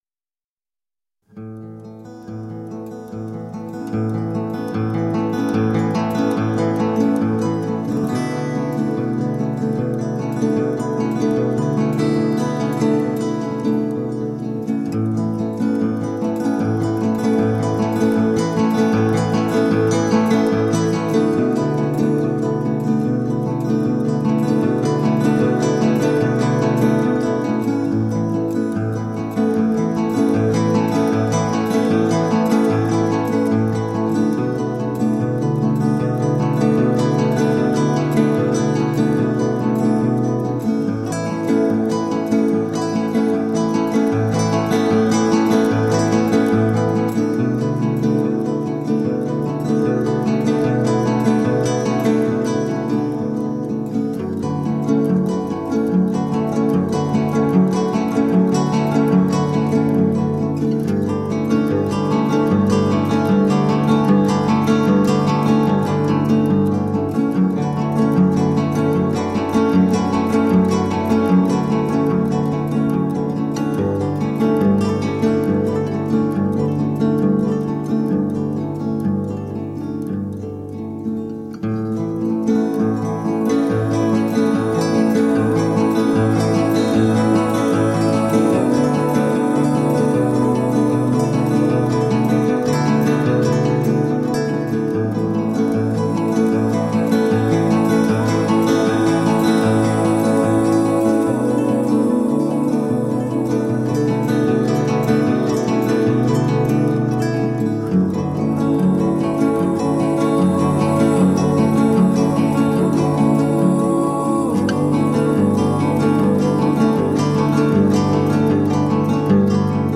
Layers of lush acoustic guitar.